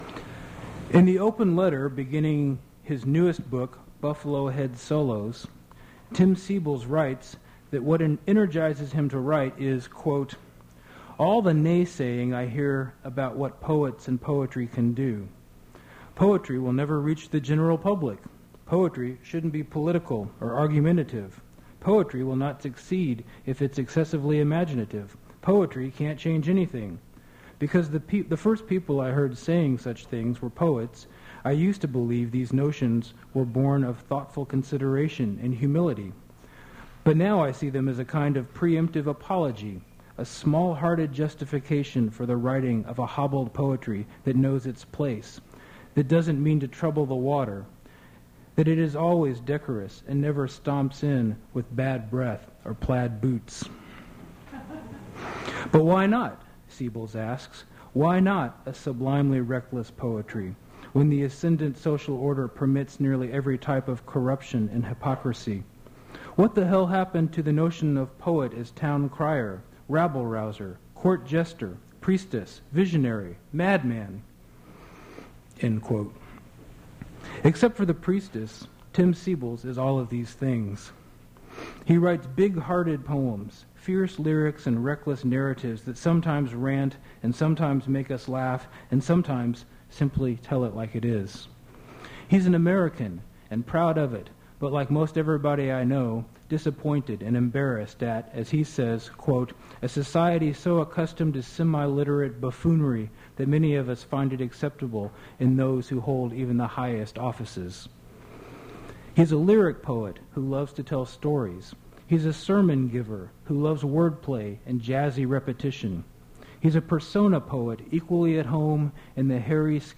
Poetry reading featuring Tim Seibles
Attributes Attribute Name Values Description Tim Seibles poetry reading at Duff's Restaurant.
mp3 edited access file was created from unedited access file which was sourced from preservation WAV file that was generated from original audio cassette.
Cut white noise and irrelevant comment at beginning